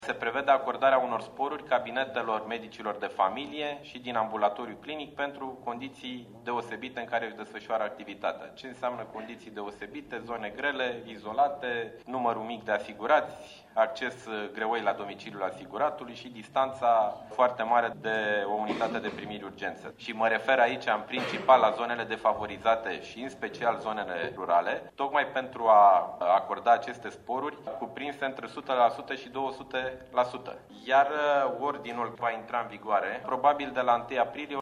Vor putea alege între panseluţe şi a avea totuşi medic reanimator – spune ministrul sanatatii, Nicolae Banicioiu: